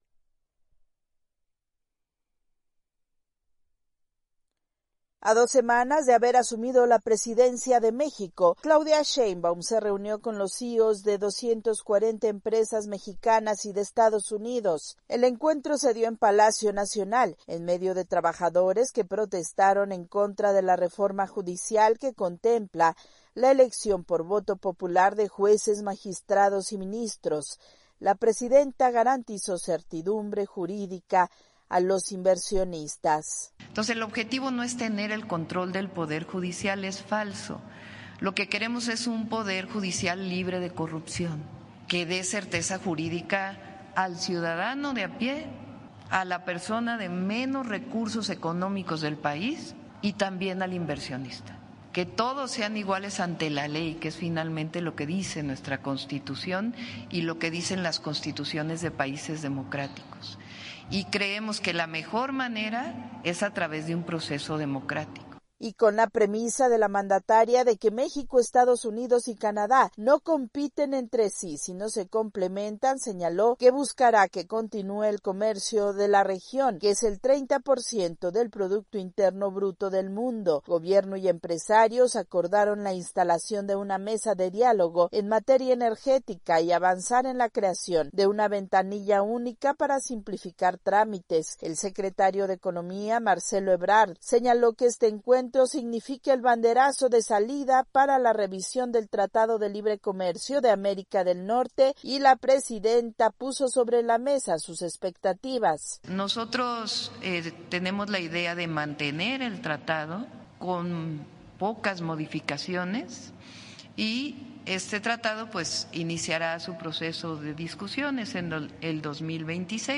Luego de reunirse con empresarios de Estados Unidos y México, la presidenta Claudia Sheinbaum anunció inversiones por 20 mil millones de dólares y pese a las protestas contra la reforma al poder judicial, garantizó que hay certeza para inversionistas. Desde Ciudad de México informa